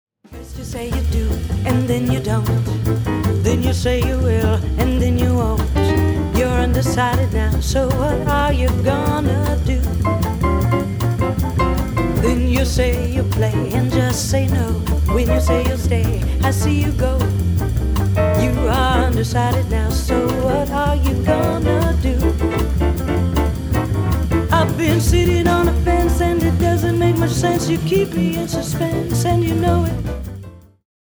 A play-along track in the style of jazz.